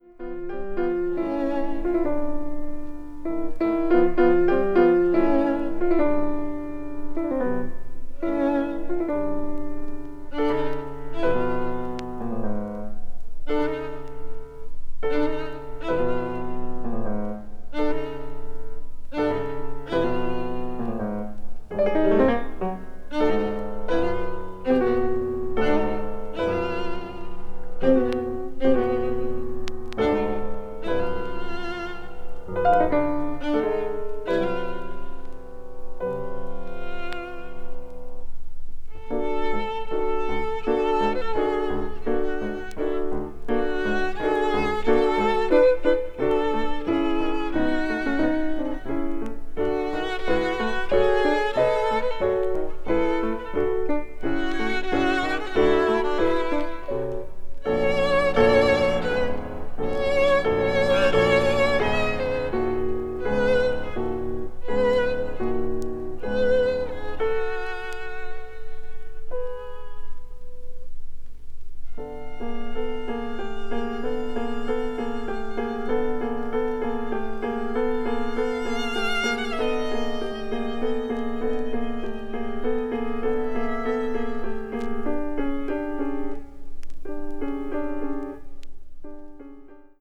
media : EX-/EX-(わずかなチリノイズ/一部軽いチリノイズが入る箇所あり,再生音に影響ない薄い擦れあり)